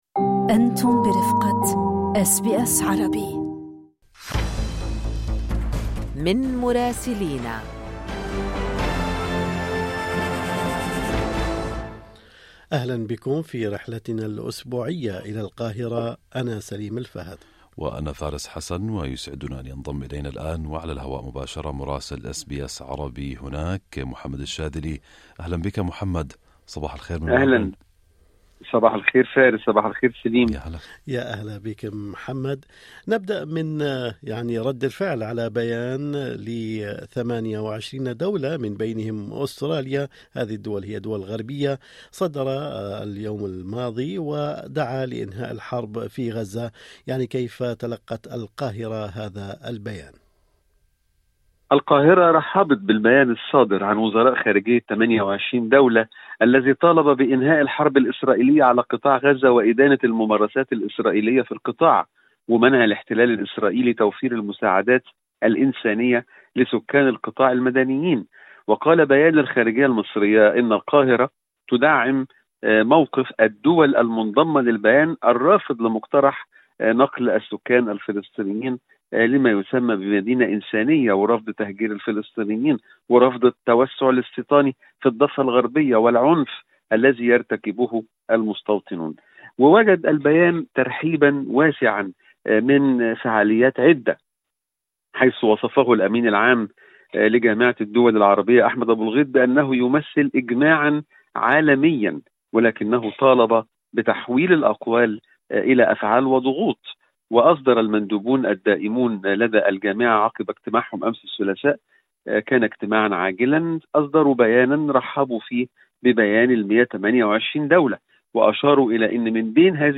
أزمة قبلات الساحل الشمالي وموقف مصر من بيان صادر عن 28 دولة غربية حول حرب غزة في تقرير مراسلنا في القاهرة